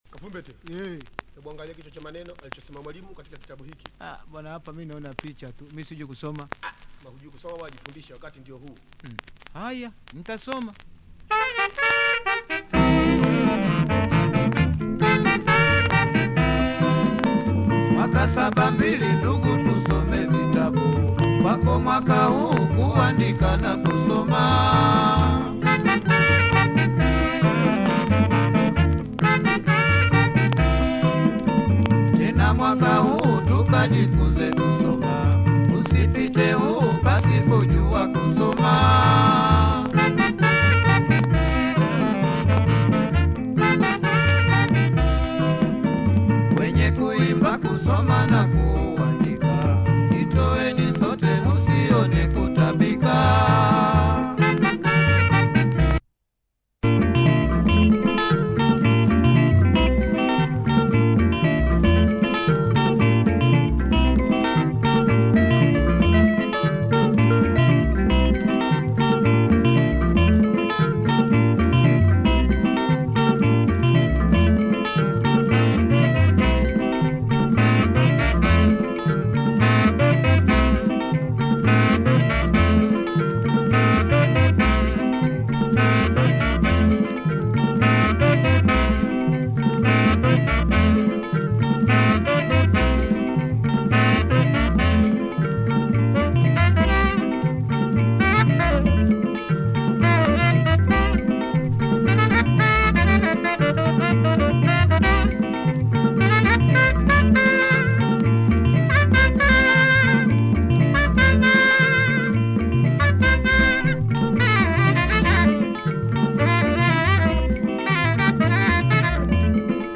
great vibe overall